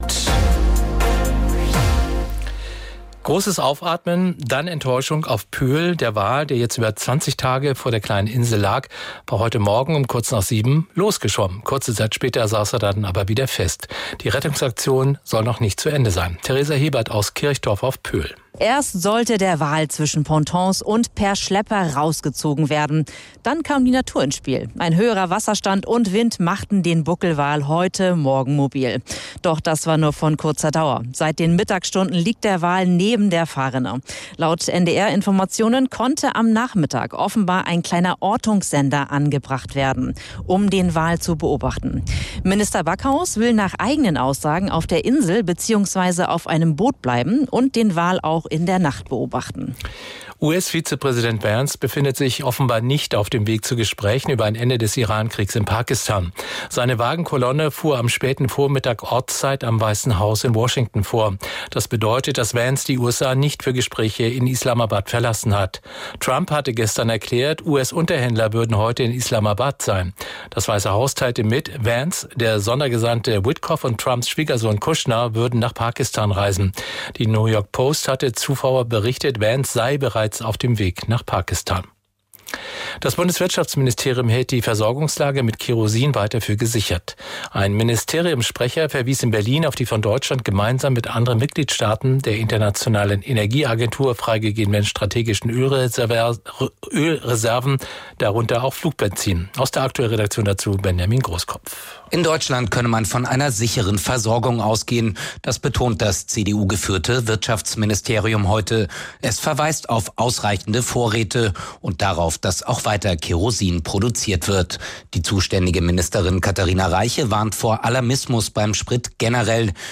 Nachrichten und Informationen aus Mecklenburg-Vorpommern, Deutschland und der Welt von NDR 1 Radio MV.